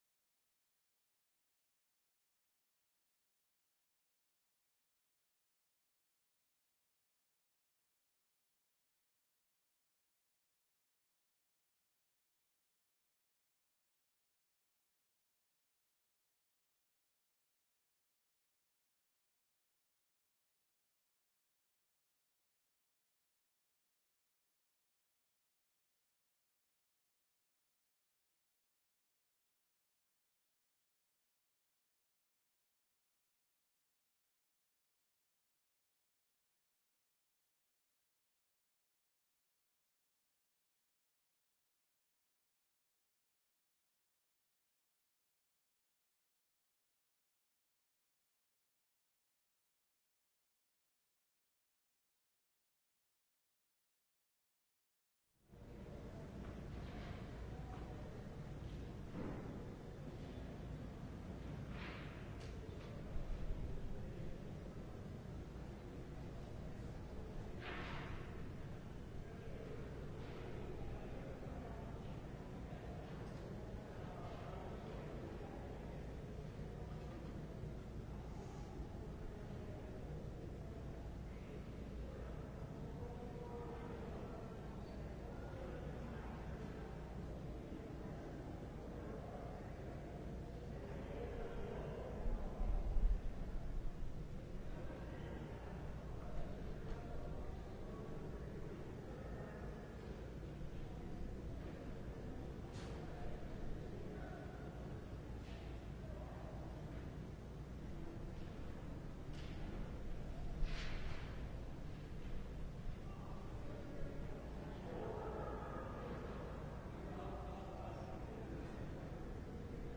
LIVE Evening Worship Service - For You Died